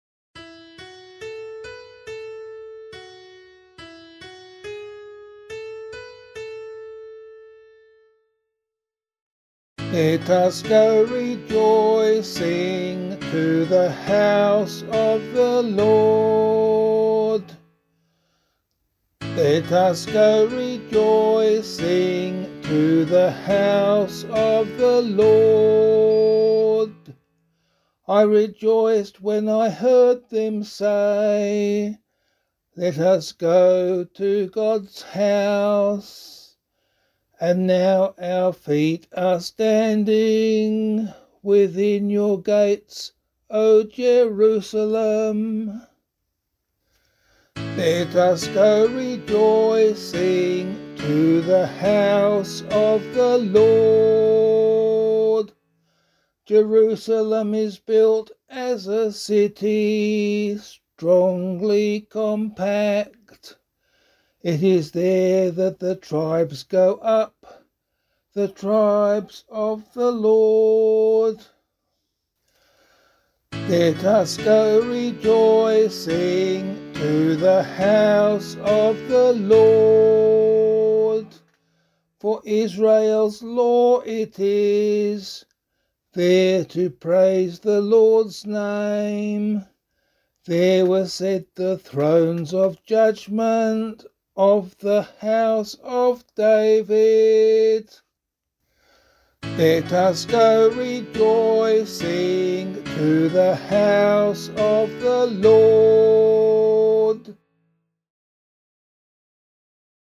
068 Christ the King Psalm C [LiturgyShare 8 - Oz] - vocal.mp3